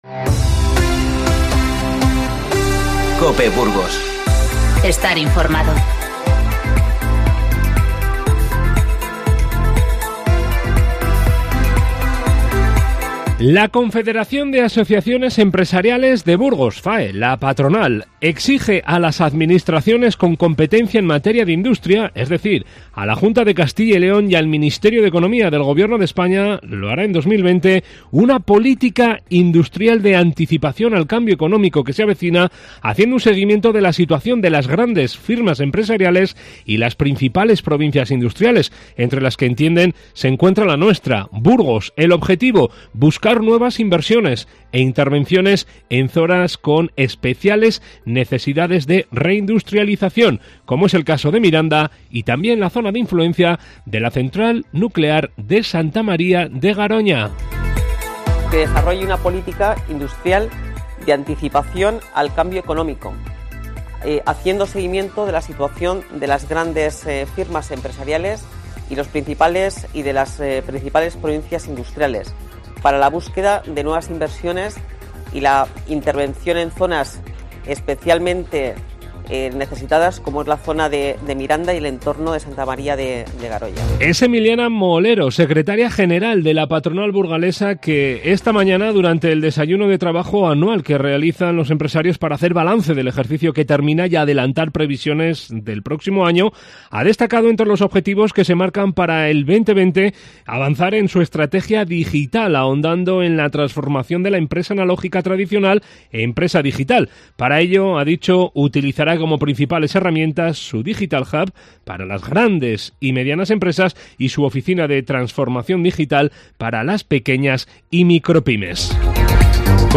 INFORMATIVO Mediodía 19-12-19